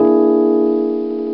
E Piano Aug Sound Effect
Download a high-quality e piano aug sound effect.
e-piano-aug.mp3